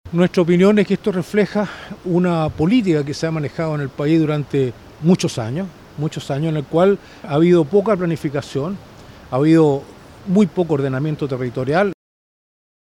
Las declaraciones fueron vertidas en el marco de la inauguración de la feria de la innovación y Gestión Ambiental a la que también asistió el subsecretario de medio ambiente, Jorge Canals, quien no quiso referirse a la situación en Til Til.